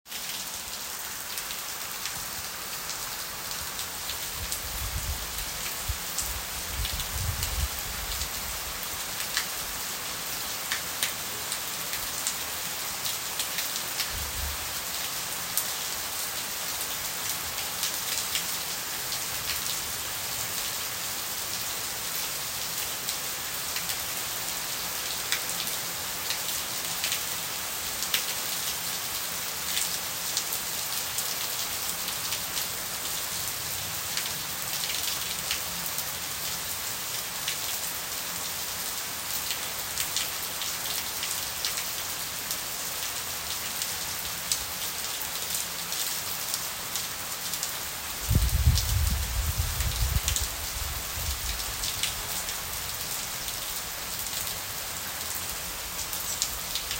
This was by no means as intense as the other two, but at the time I was there, there was a water shortage due to lack of rain and river contamination. This was the first real down pour in over 3 months. I also like the sound of the rain bouncing off the bamboo structure.
cultivare_rain.m4a